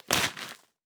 Notes Scrunched In Wallet.wav